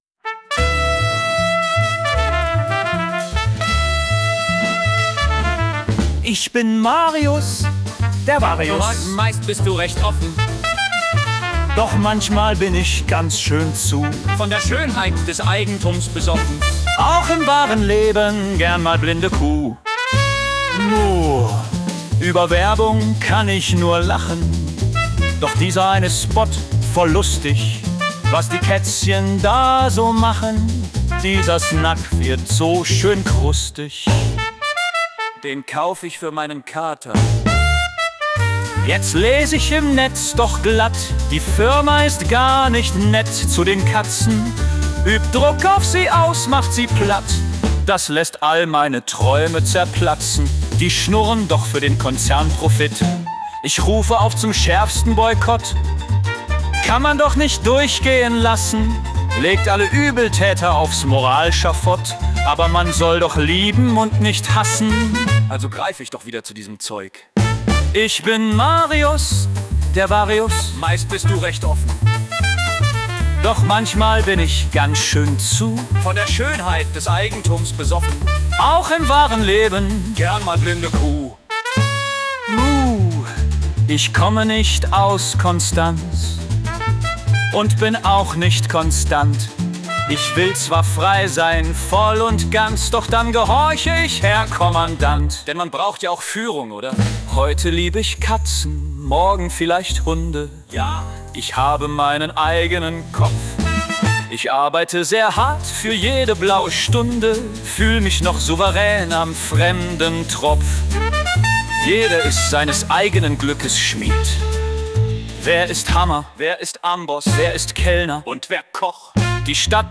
Version 2: Von dieser Jazz-Variante sind ebenfalls Auszüge in Folge 3 meines Podcasts zu hören.
Marius-10-Jazz.wav